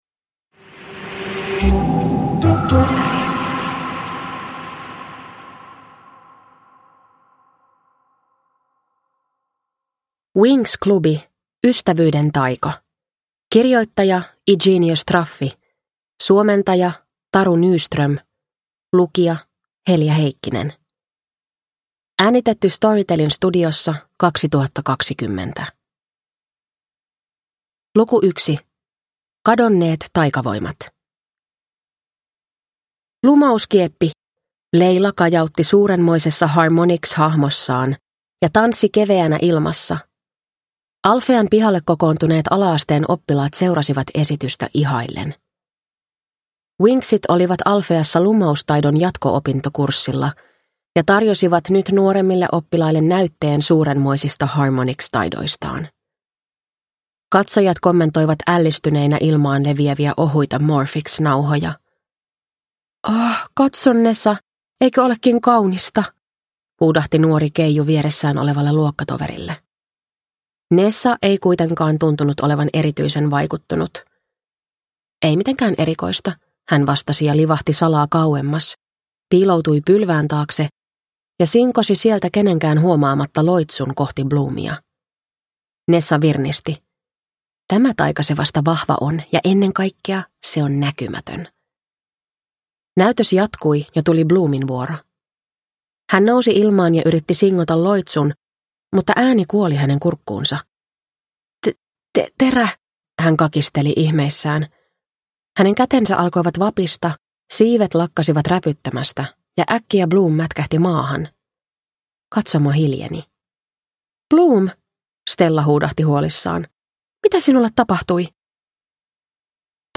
Winx - Ystävyyden taika – Ljudbok